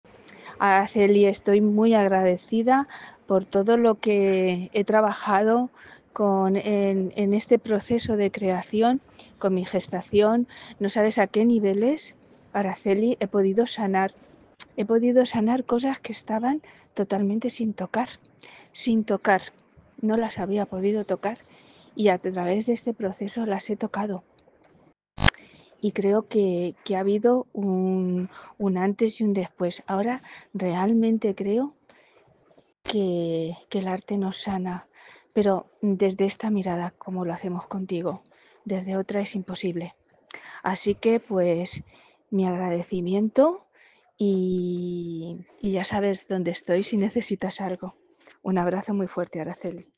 Esto es lo que dicen quienes ya lo han vivido
Testimonio-en-audio.mp3